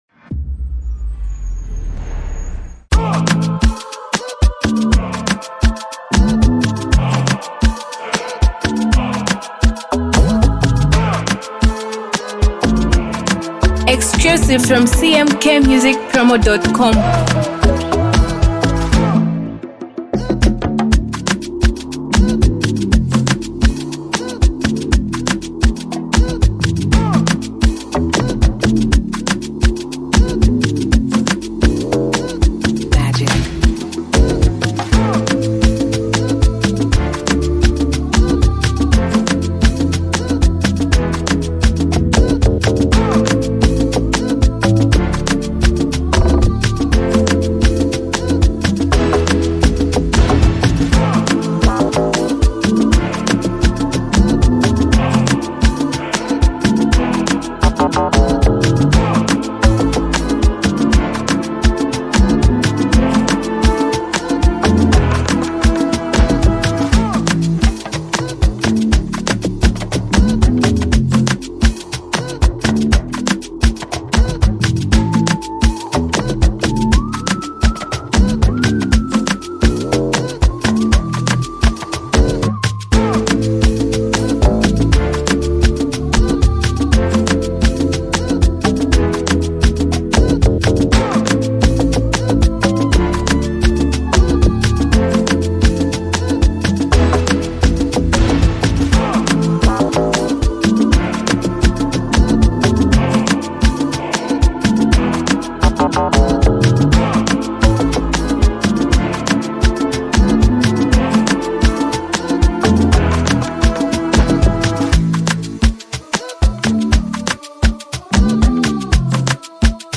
Vibe to the new afro beat instrument